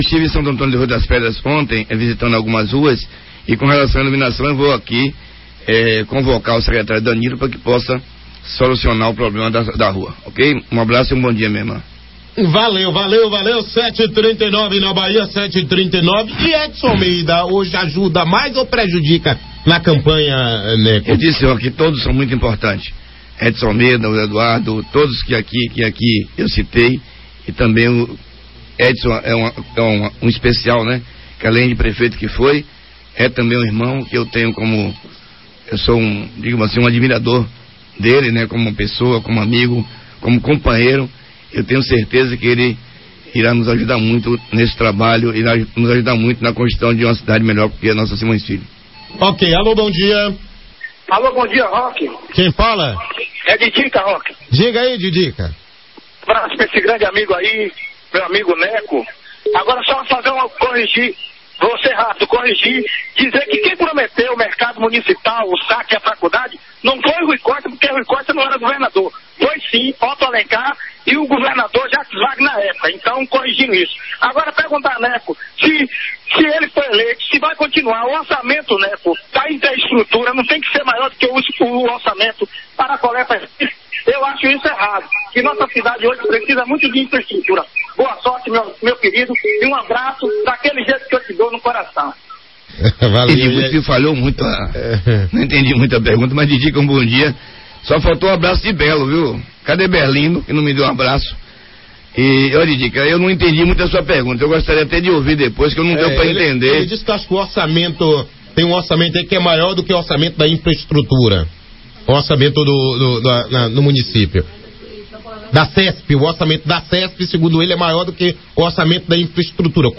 Em entrevista na manhã desta quinta-feira